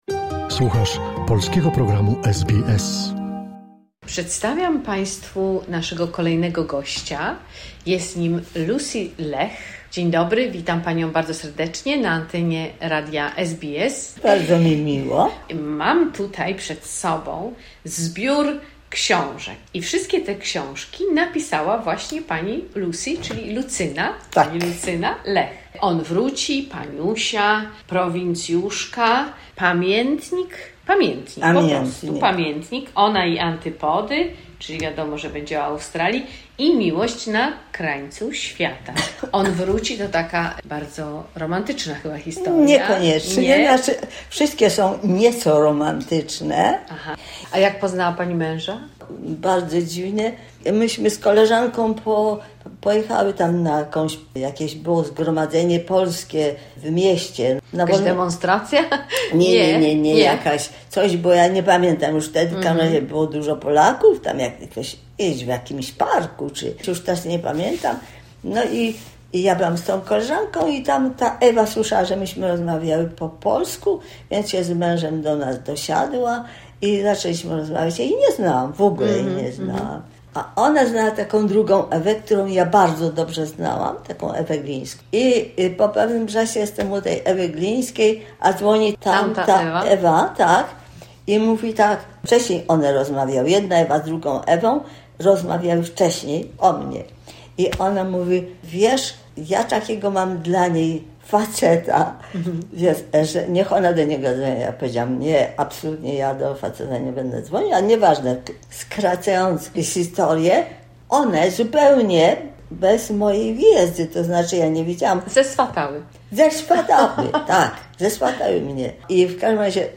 Druga część rozmowy